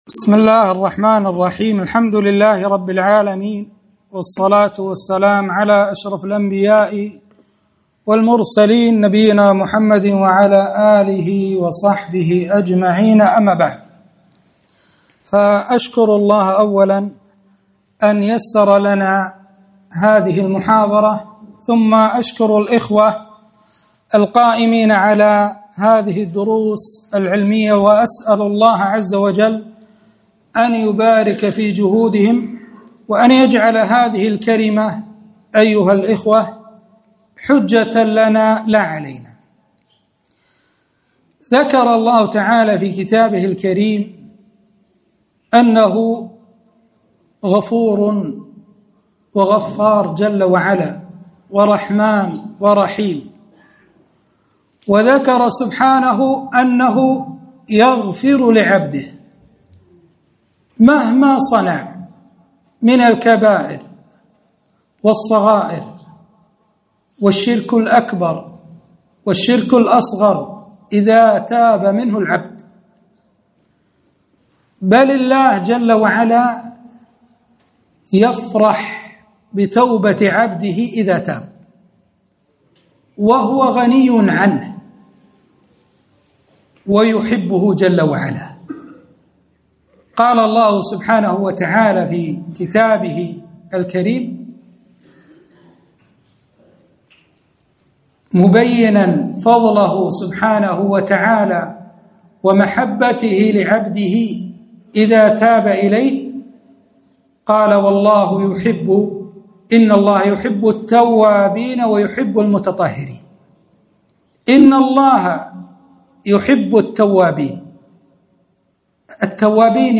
محاضرة - (إن الحسنات يذهبن السيئات)